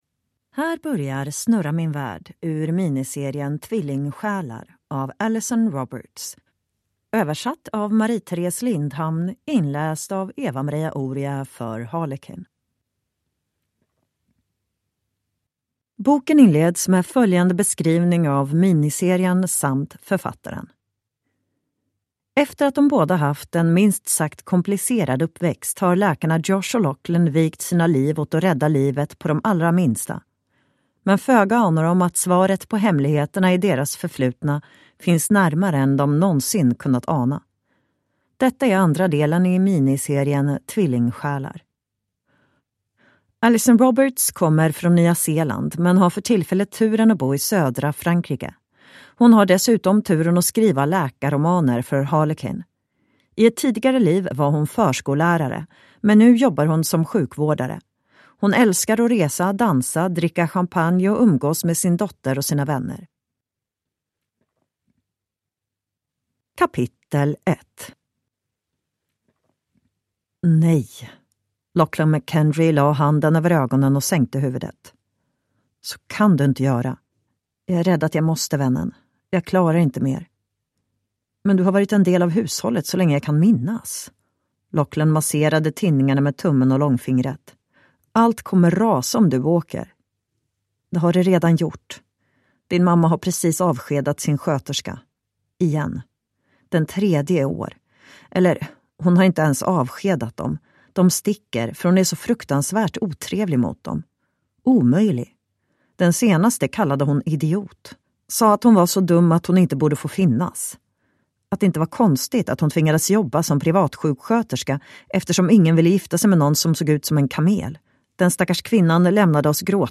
Snurra min värld (ljudbok) av Alison Roberts